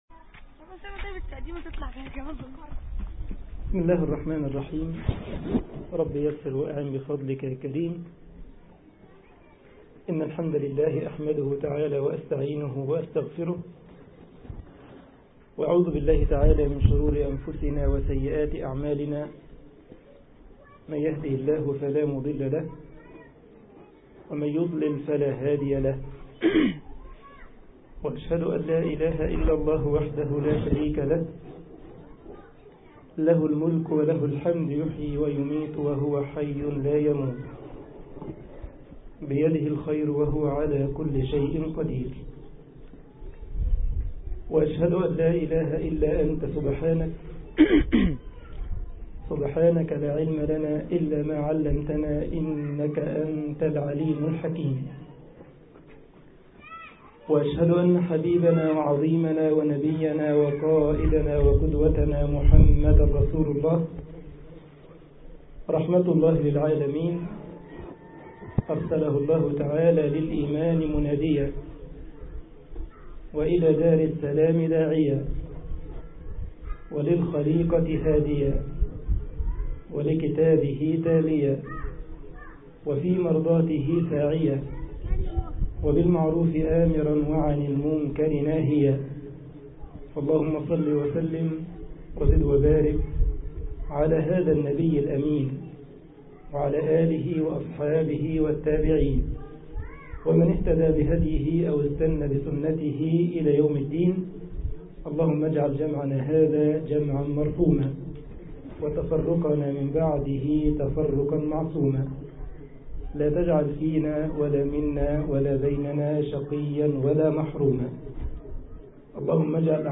Muhadhara_kalima muwajaha lil okht al moslima_Aegypt_2013.mp3